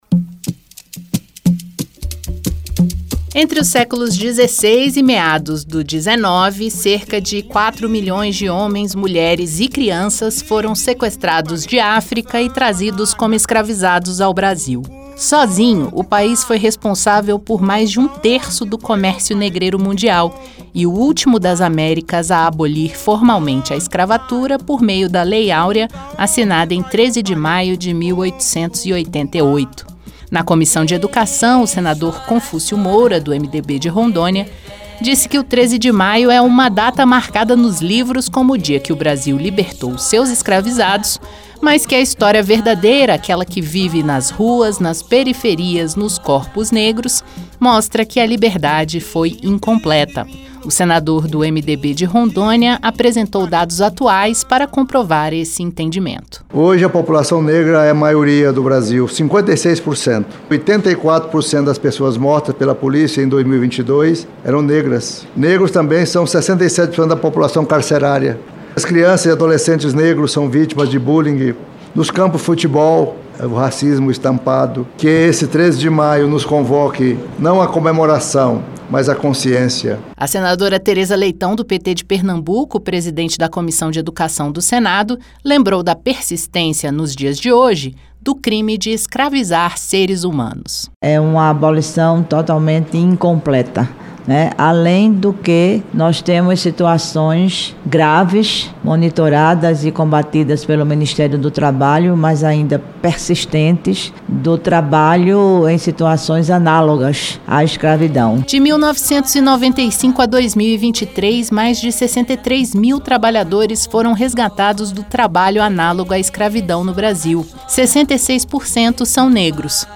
13 de maio: senadores comentam impactos da escravatura no Brasil de hoje